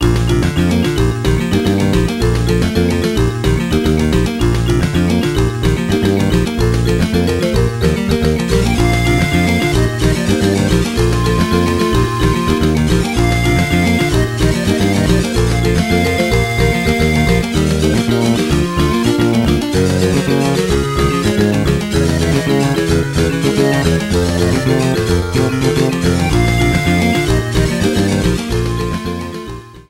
Clipped and applied fade-out with Audacity.